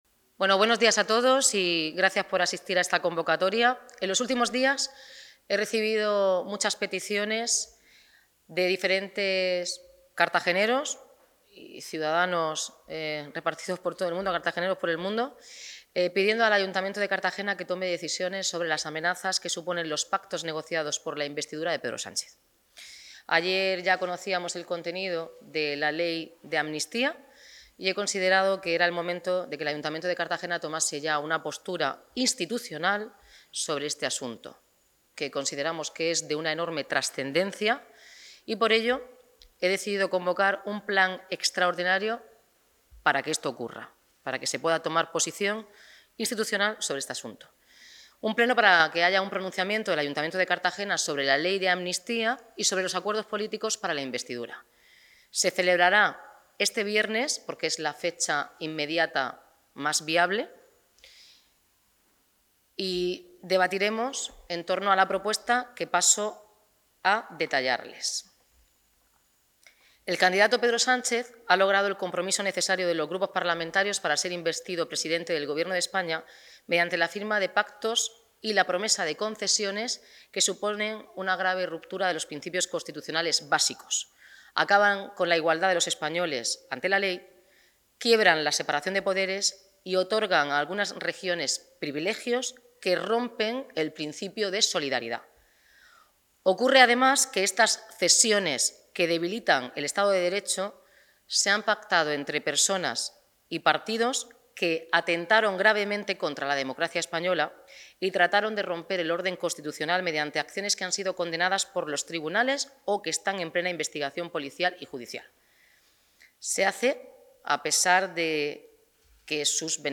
Enlace a Declaraciones de la alcaldesa Noelia Arroyo sobre la convocatoria del Pleno Extraordinario